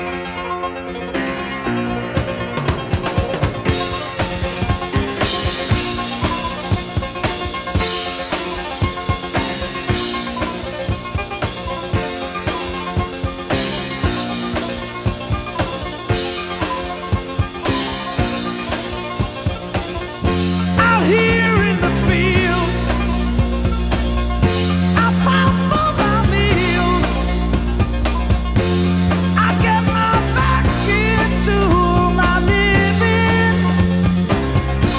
Жанр: Пост-хардкор / Хард-рок / Рок
# Hard Rock